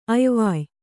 ♪ ayvāy